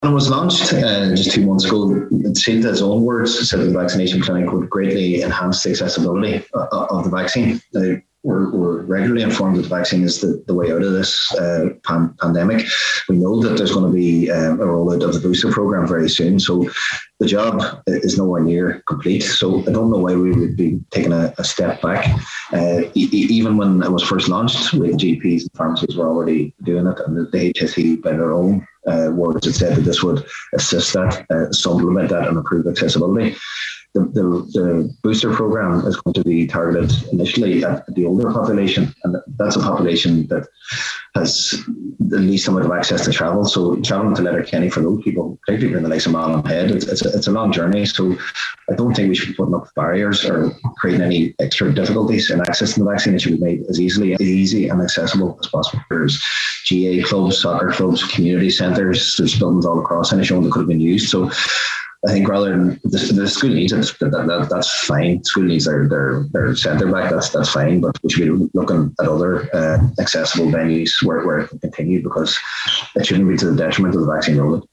Councillor Jack Murray says rather than removing the service from Inishowen, alternative venues should be considered.